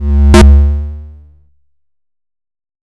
swerve.wav